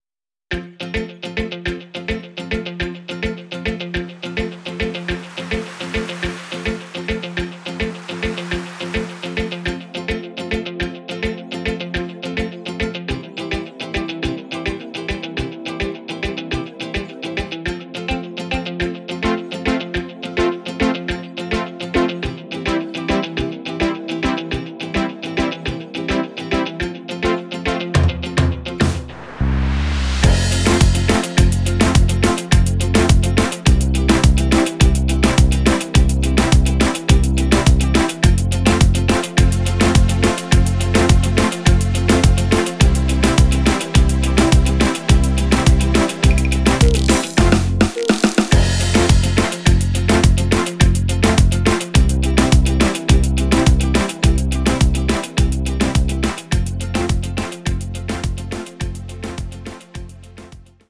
neue moderne Dance Version